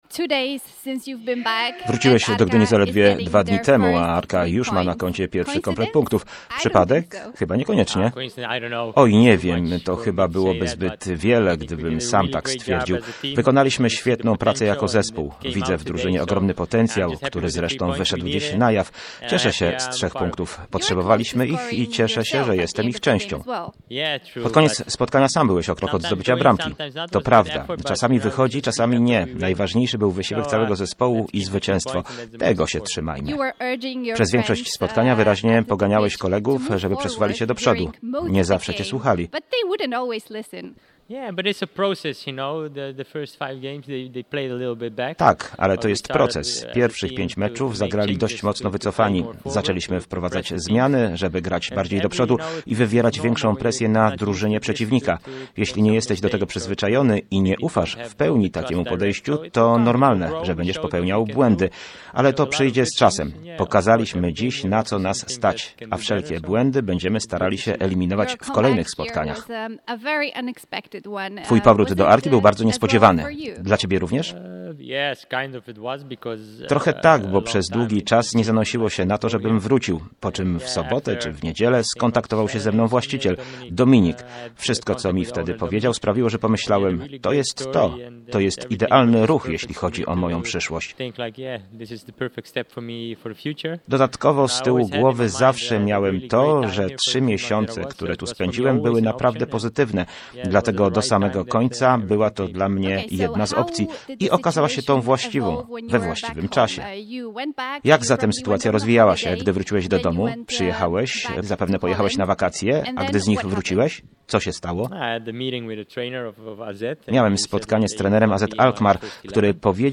– To dla mnie wielki zaszczyt, że Arka chciała i zrobiła wszystko po to, by mnie pozyskać – mówił po meczu do mikrofonu Radia Gdańsk były już pomocnik AZ Alkmaar, który na własną prośbę podpisał z gdyńskim klubem długi, jak na polskie warunki, 3-letni kontrakt.